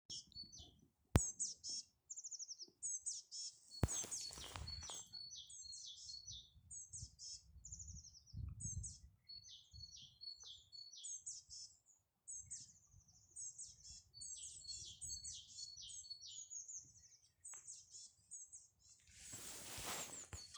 Mizložņa, Certhia familiaris